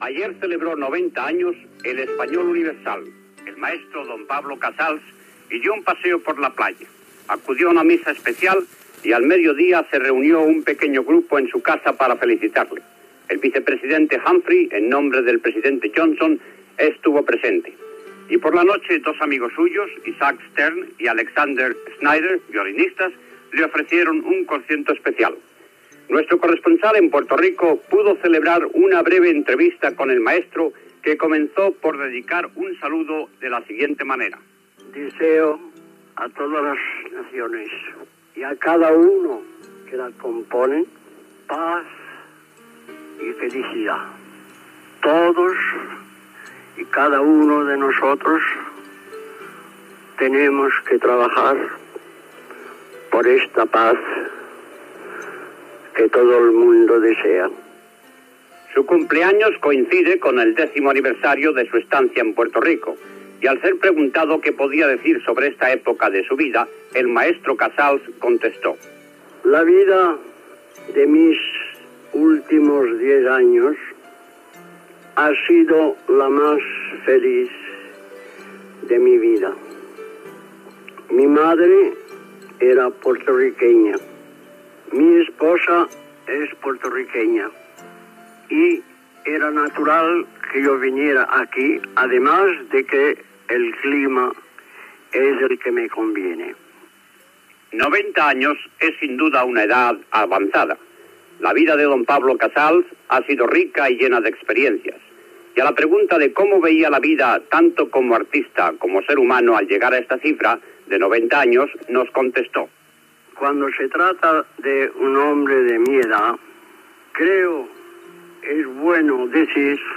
Informació sobre el 90è aniversari de Pau Casals i declaracions del músic sobre la seva via a Puerto Rico i la vellesa